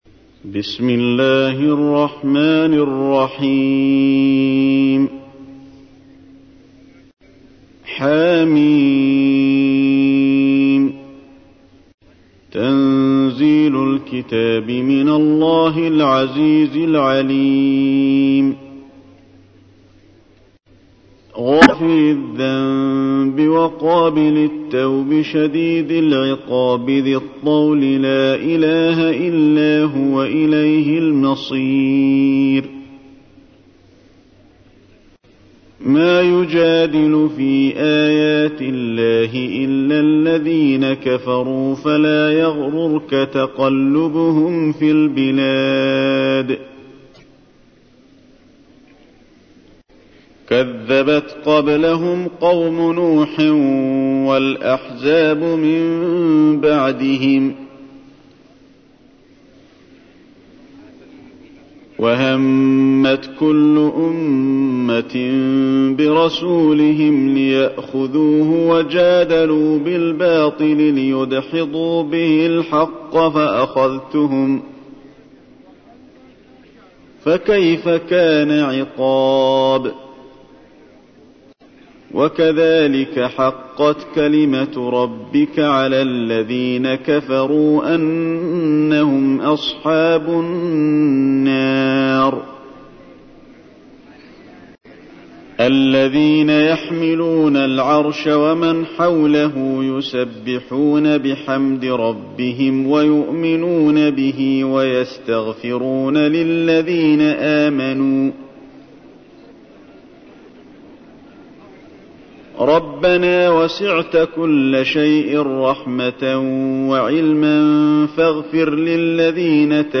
تحميل : 40. سورة غافر / القارئ علي الحذيفي / القرآن الكريم / موقع يا حسين